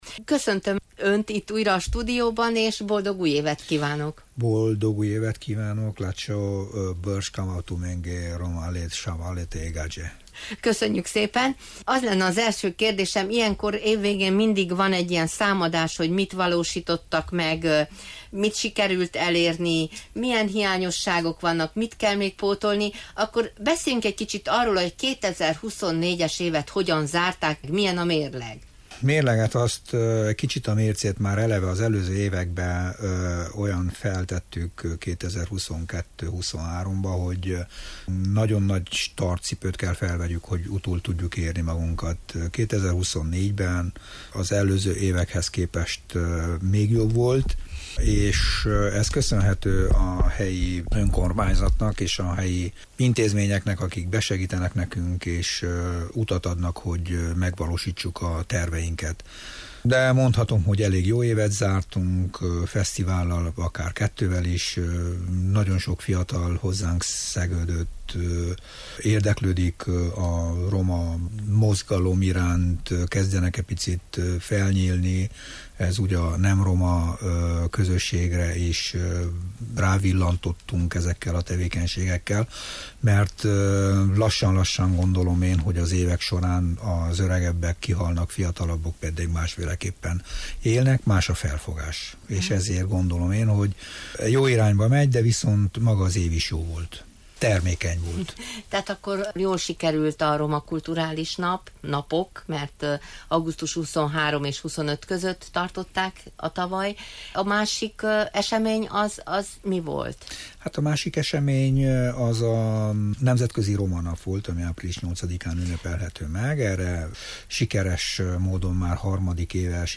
akivel a rádióstúdióban megvontuk a tavalyi év mérlegét, és az idei kilátásokról, tervekről beszélgettünk.